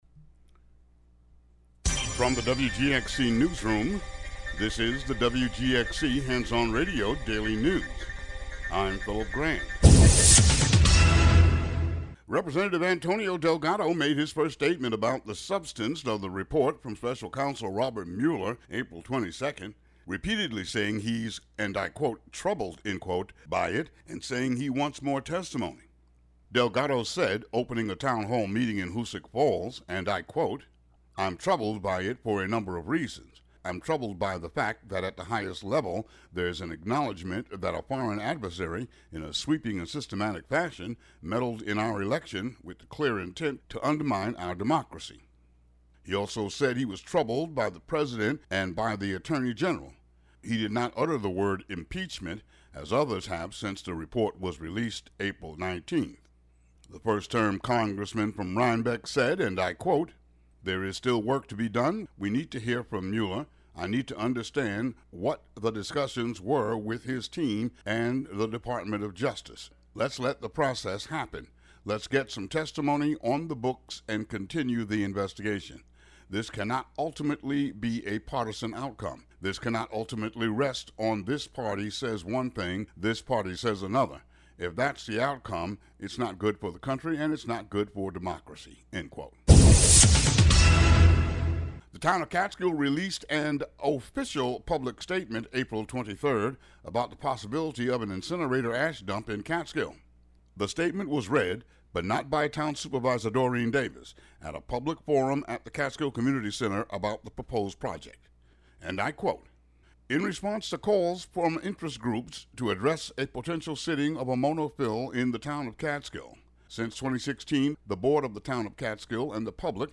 Today's daily news.